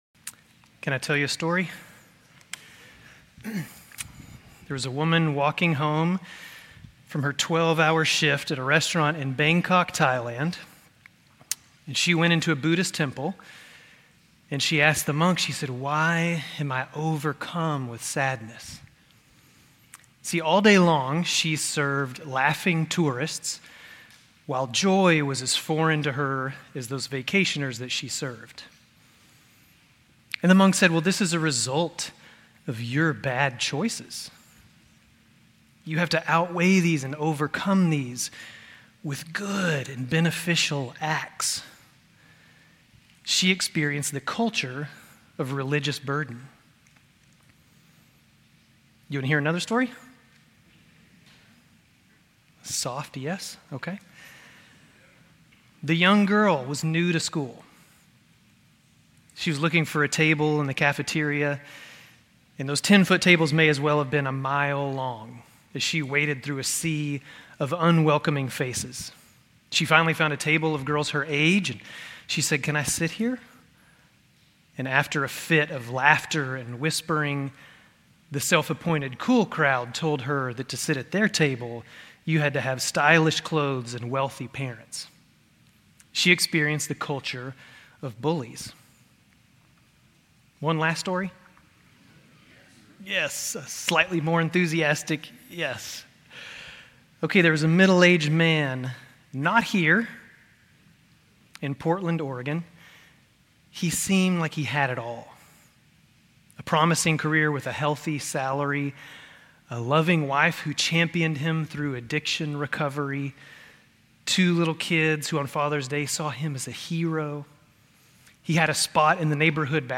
Grace Community Church Lindale Campus Sermons Galatians 6:11-18 Jun 17 2024 | 00:21:41 1x 00:00 / 00:21:41 Subscribe Share RSS Feed Share Link Embed